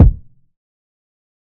TC Kick 06.wav